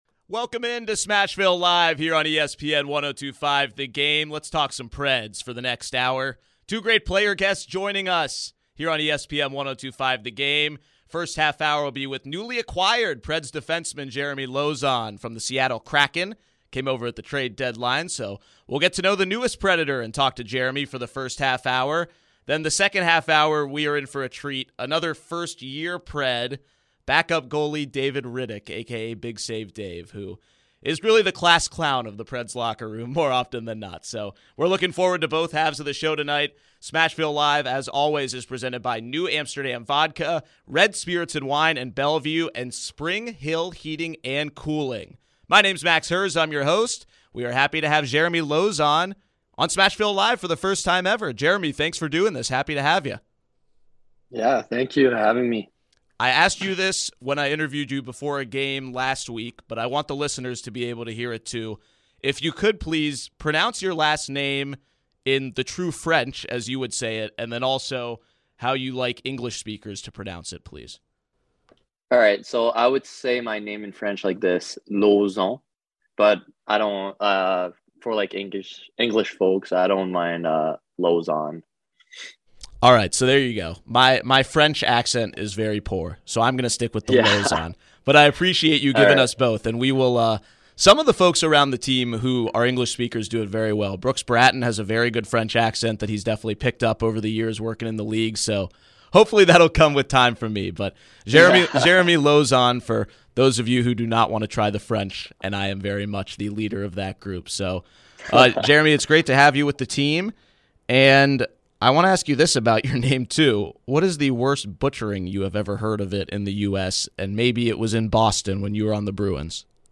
Preds defenseman Jeremy Lauzon